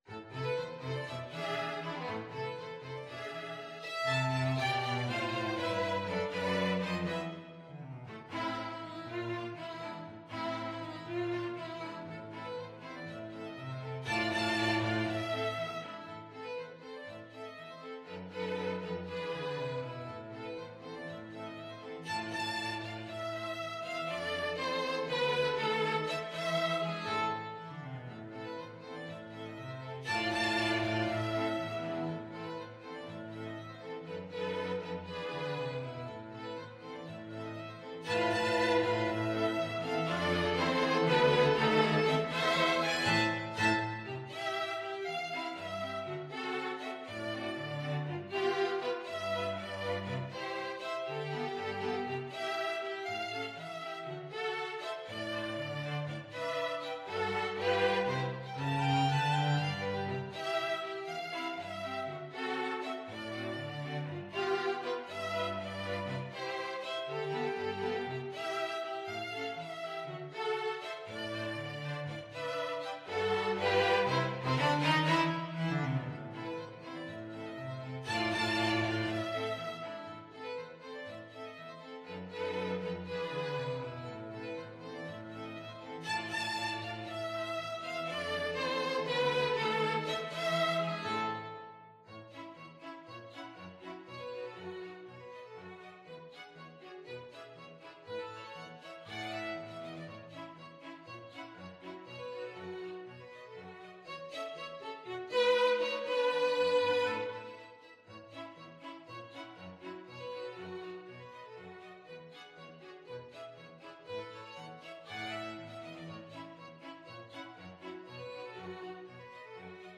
Violin 1Violin 2ViolaCello
Allegretto Misterioso = 120
2/4 (View more 2/4 Music)
Classical (View more Classical String Quartet Music)